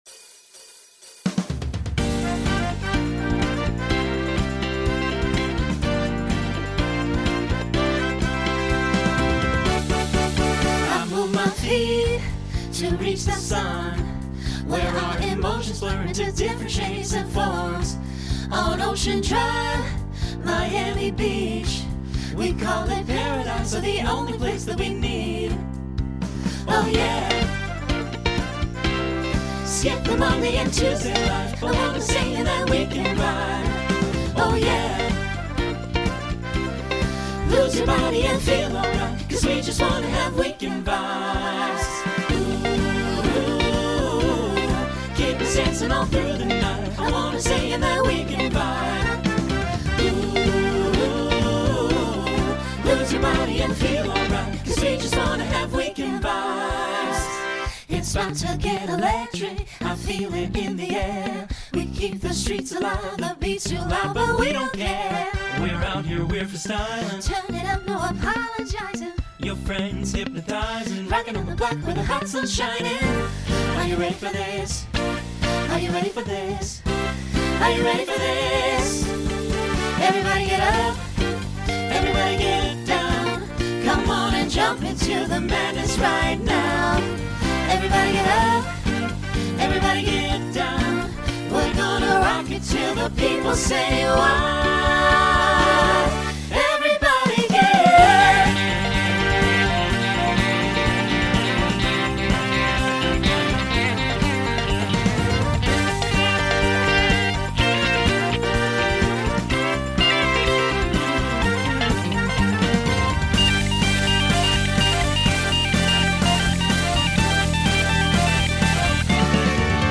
New SSA voicing for 2024.
Genre Pop/Dance , Rock
Voicing SATB , SSA